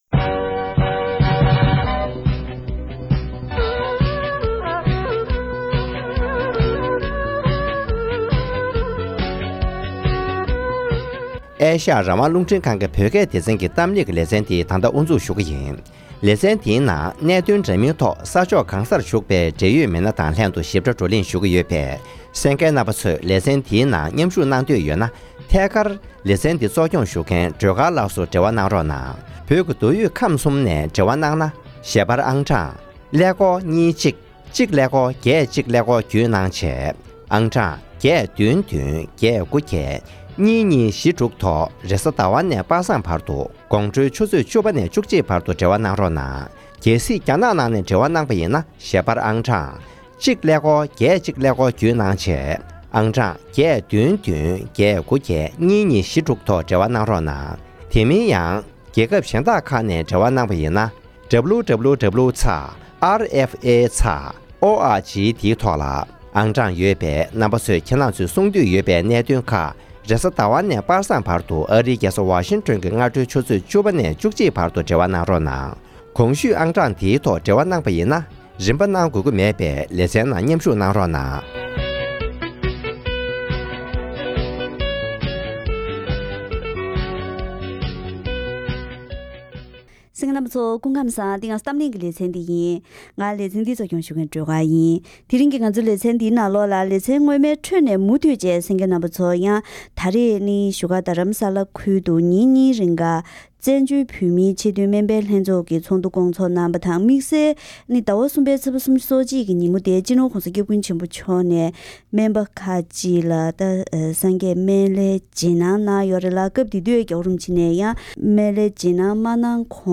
༄༅༎ཐེངས་འདིའི་གཏམ་གླེང་ལེ་ཚན་ནང་དུ།